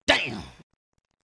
Tags: hip hop